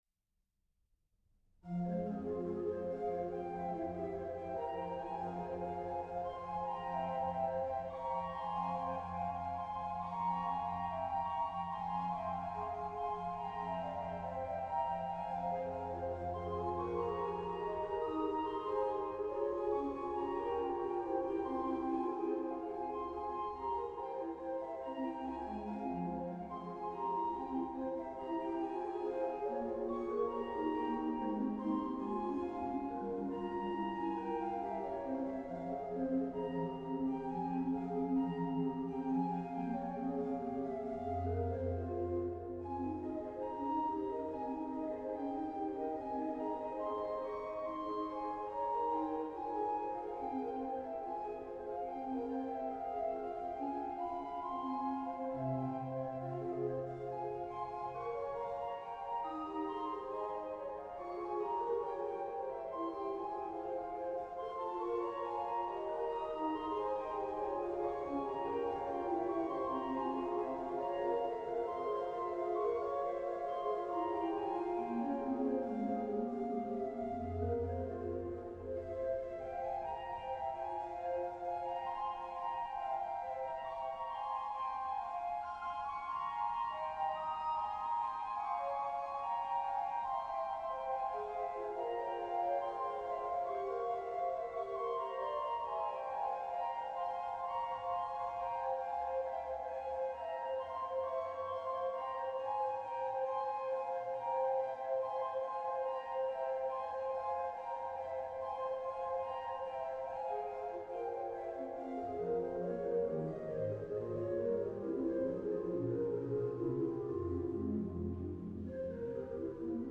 Traktura mechanická, kuželkové vzdušnice, barkerová páka.
improvizace